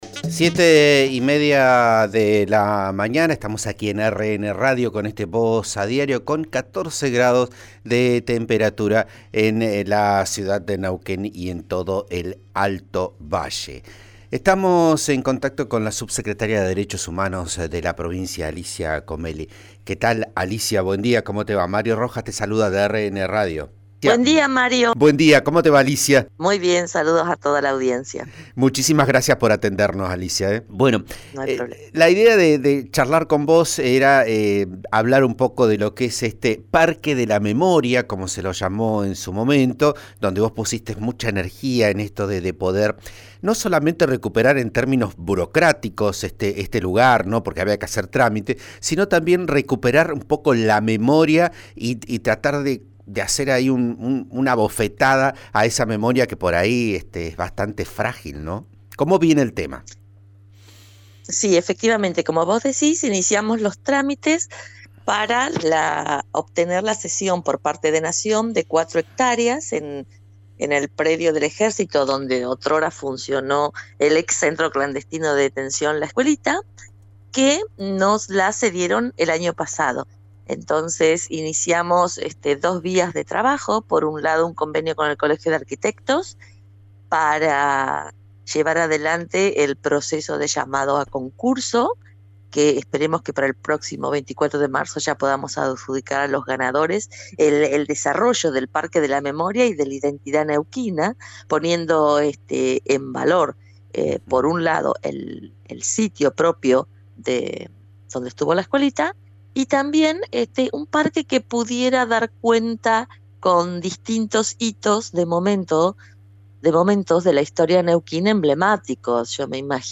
La subsecretaria de Derechos Humanos de la provincia, Alicia Comelli, dialogó con Vos a Diario de Radio RN acerca de los hallazgos de restos óseos.